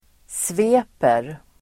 Uttal: [sv'e:per]